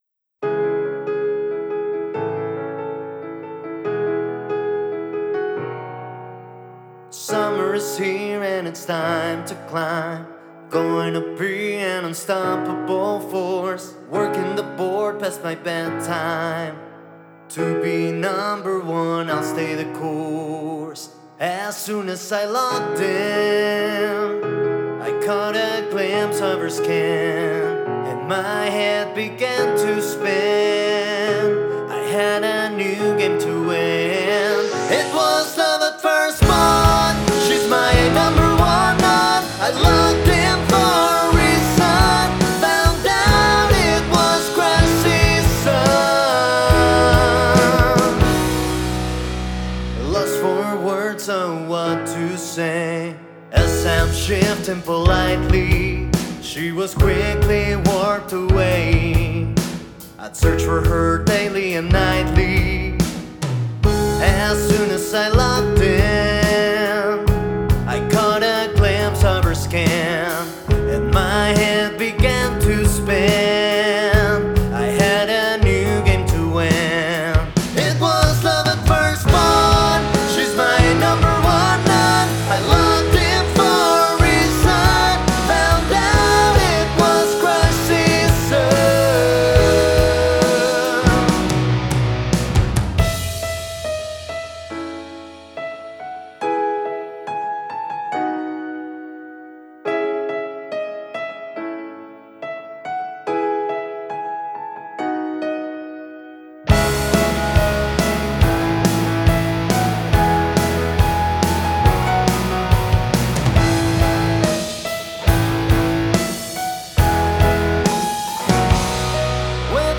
It's played twice in the prechorus. fingers crossed